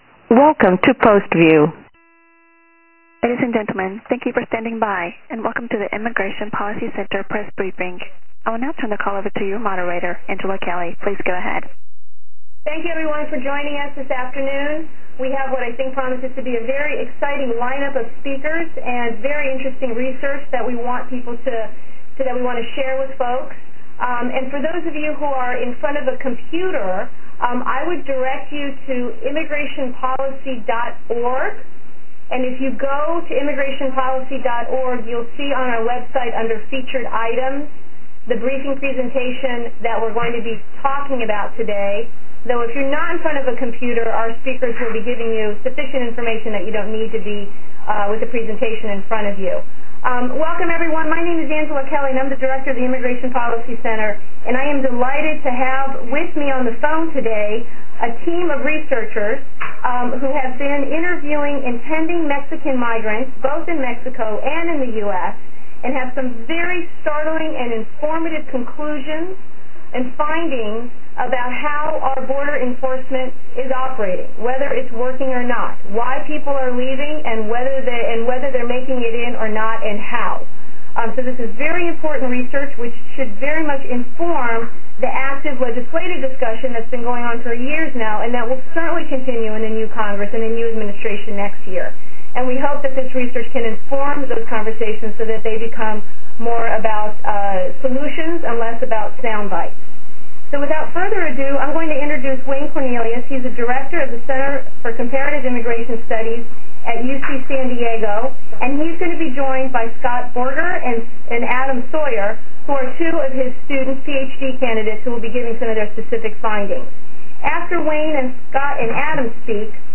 A briefing with experts recently featured in the PBS series “Frontline,” who have interviewed thousands of experienced and potential migrants, studied U.S. immigration enforcement up close at the border, and reached important conclusions about our current border-enforcement efforts.
BorderTelebriefing.mp3